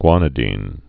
(gwänĭ-dēn)